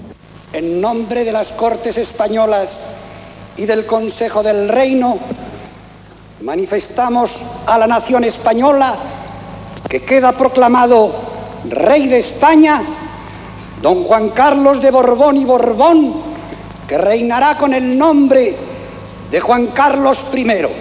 proclamació de Juan Carlos com a rei (22-Nov-1975)